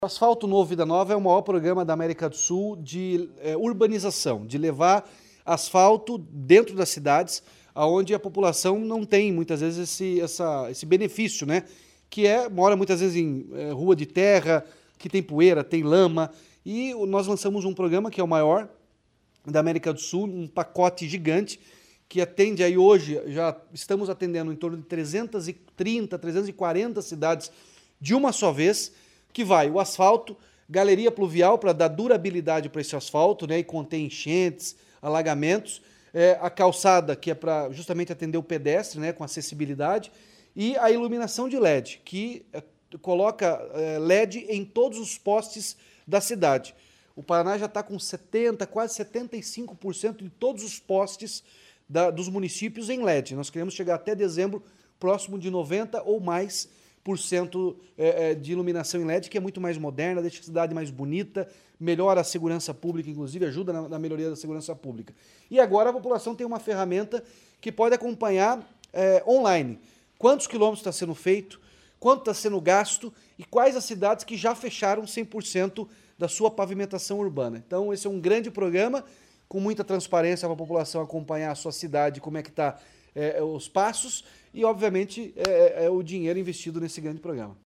Sonora do governador Ratinho Junior sobre o Asfaltômetro